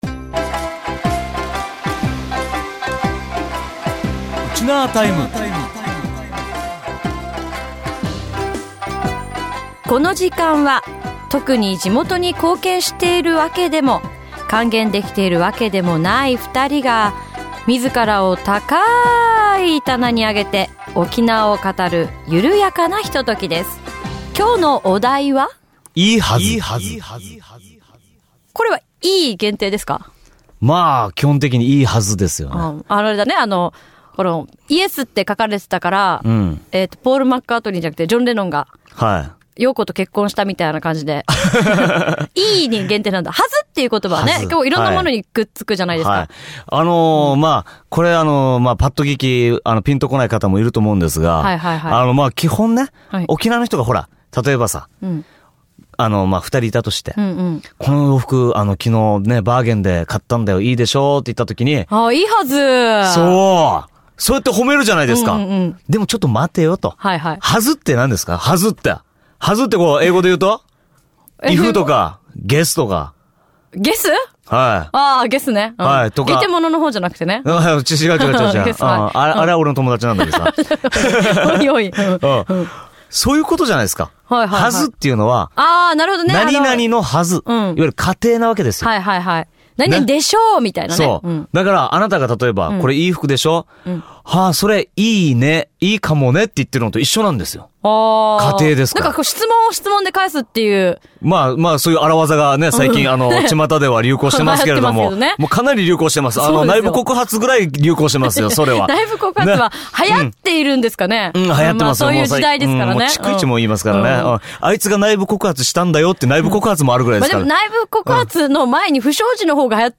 地元沖縄トーク♪